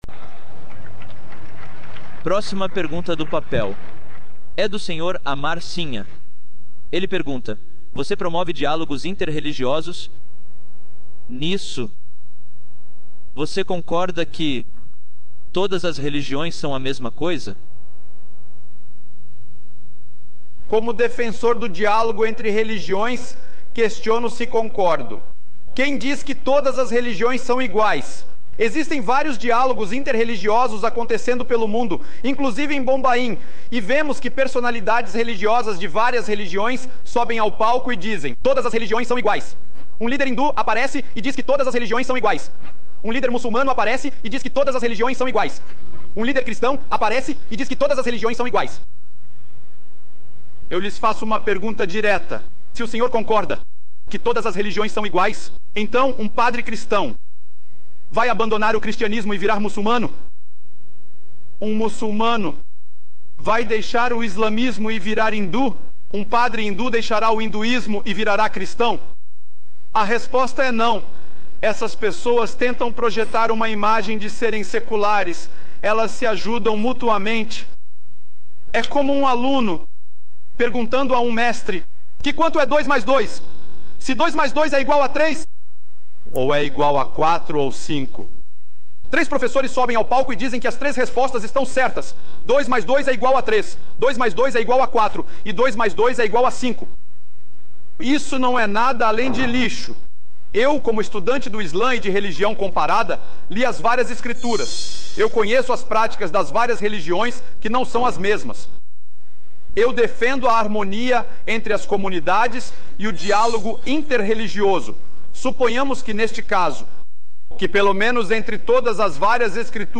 Descrição: Neste vídeo, o Dr. Zakir Naik explica por que não é justo afirmar que todas as religiões são iguais.